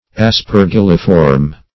Search Result for " aspergilliform" : The Collaborative International Dictionary of English v.0.48: Aspergilliform \As`per*gil"li*form\, a. [Aspergillum + -form.]